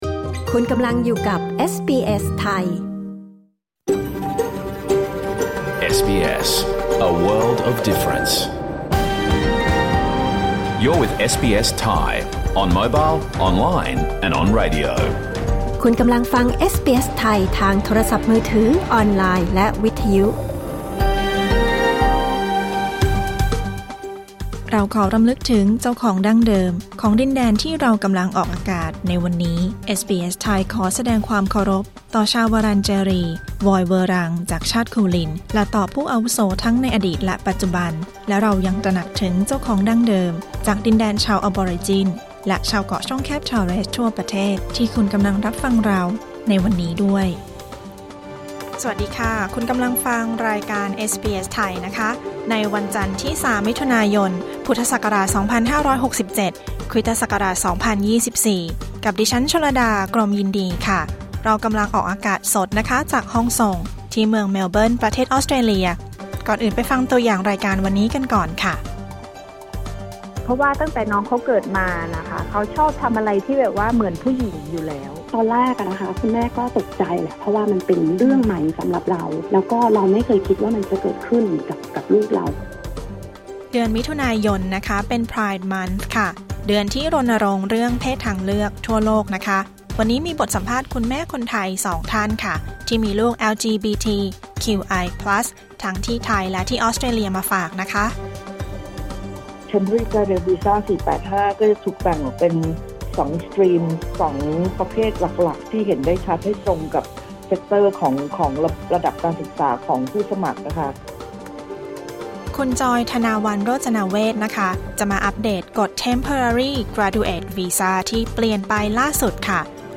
รายการสด 3 มิถุนายน 2567